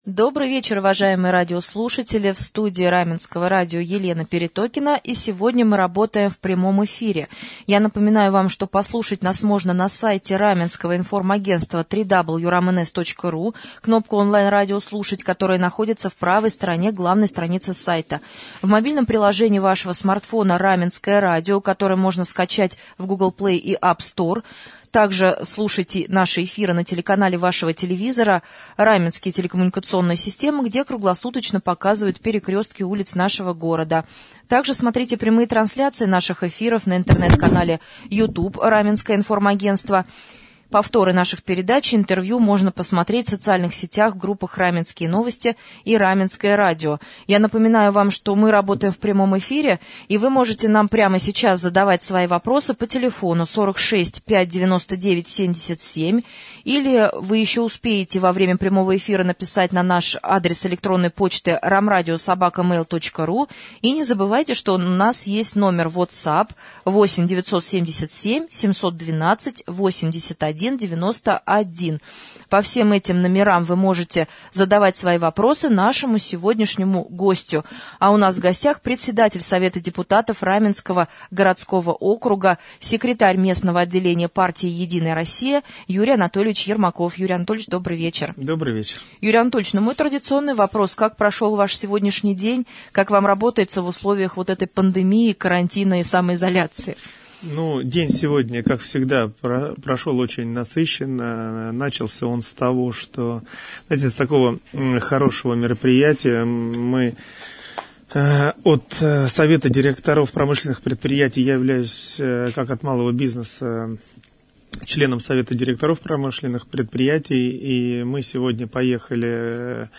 Председатель Совета депутатов Раменского г.о. стал гостем прямого эфира на Раменском радио в четверг, 21 мая.